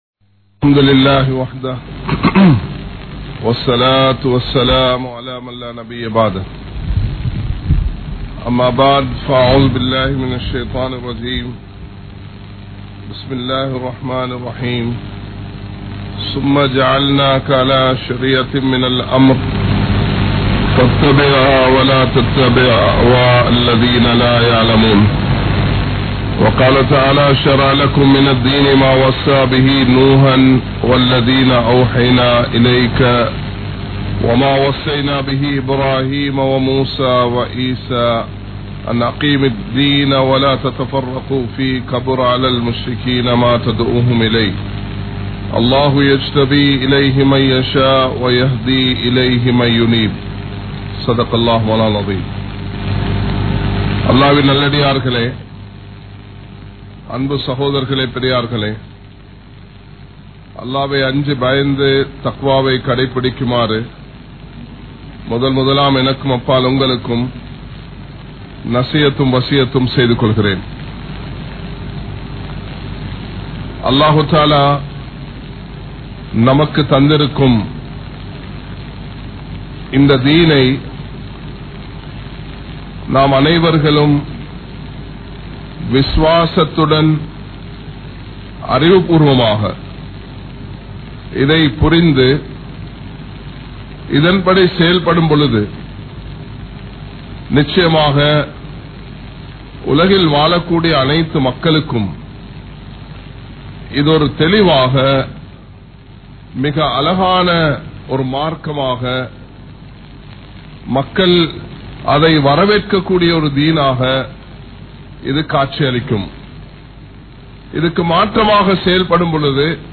Shariea (ஷரிஆ) | Audio Bayans | All Ceylon Muslim Youth Community | Addalaichenai
Colombo 03, Kollupitty Jumua Masjith